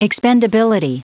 Prononciation du mot expendability en anglais (fichier audio)
Prononciation du mot : expendability